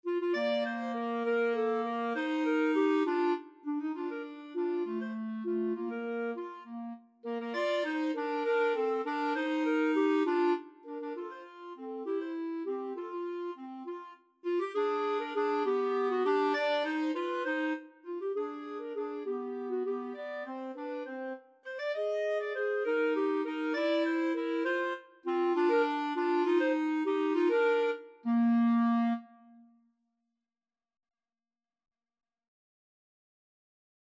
3/8 (View more 3/8 Music)
Clarinet Duet  (View more Easy Clarinet Duet Music)
Classical (View more Classical Clarinet Duet Music)